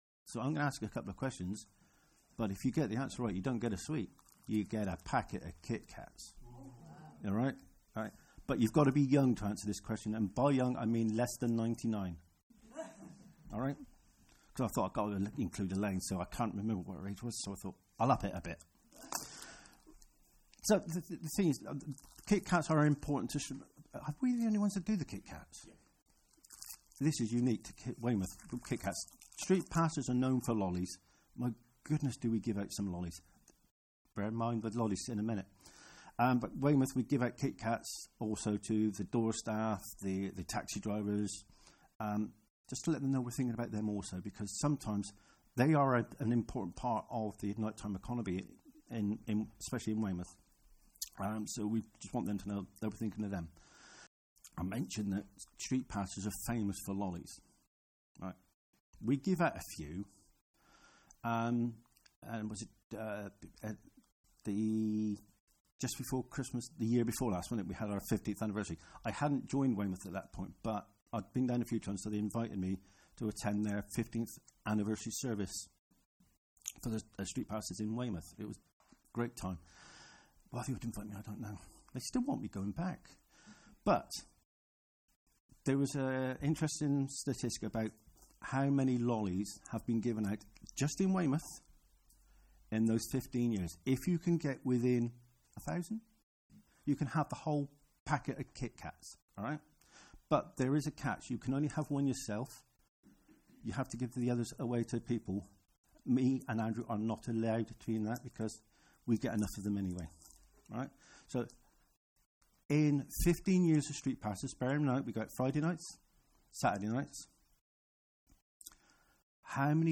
Street Pastors Testimony